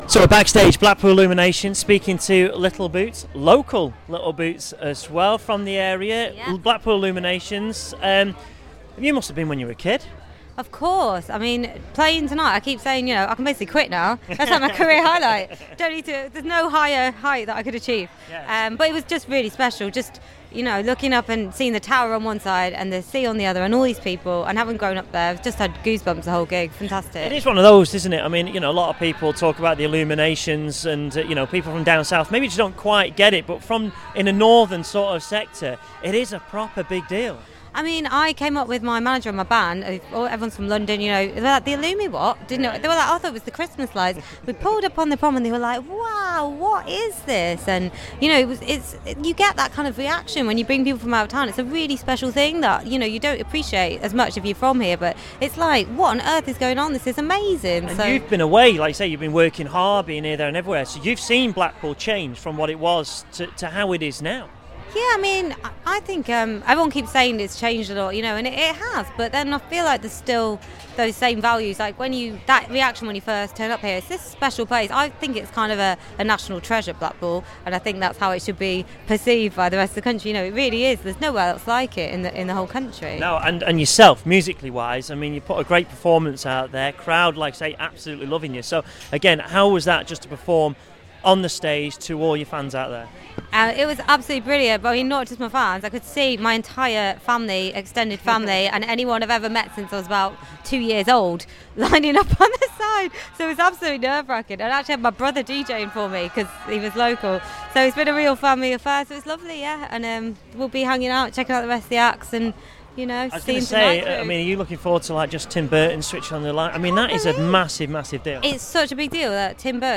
I talked to local girl Little Boots after her performance at the Illuminations Switch On 2015